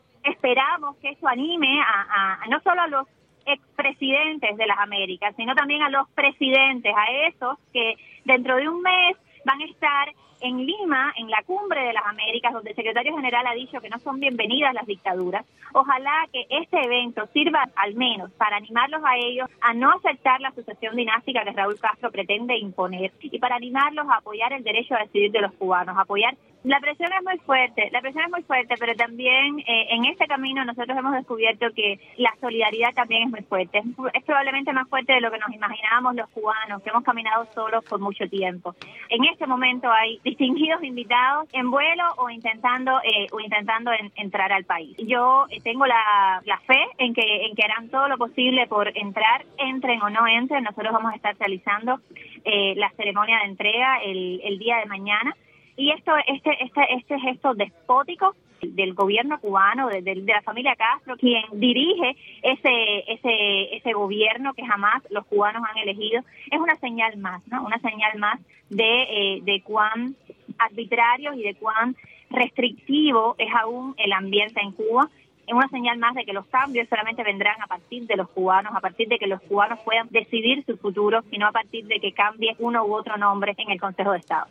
Declaraciones de Rosa María Payá